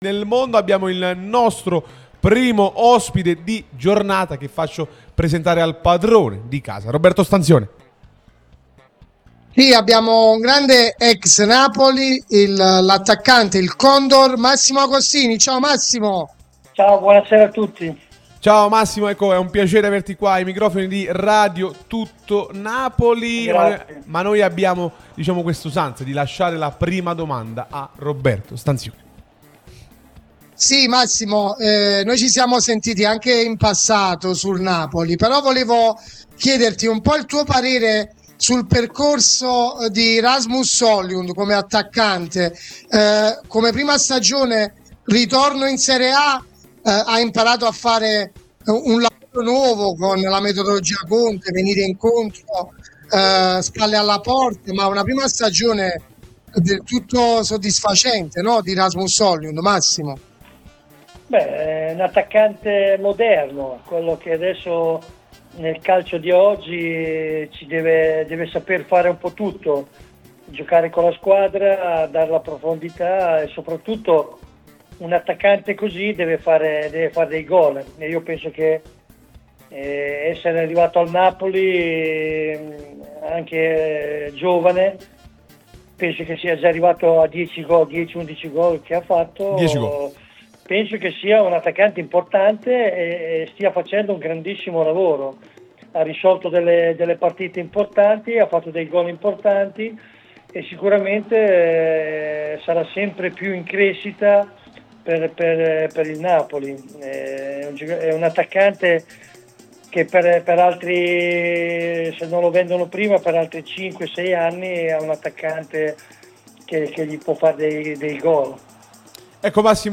L'ex attacccante di Napoli e Milan Massimo Agostini è intervenuto su Radio Tutto Napoli, l'unica radio tutta azzurra e live tutto il giorno, che puoi seguire sulle app gratuite (per Iphone o per Android, Android Tv ed LG), in DAB o qui sul sito anche in video.